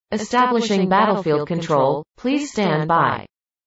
All the sounds were generated using text to speech technology.
I little echo and pitch control could make these more EVA-like...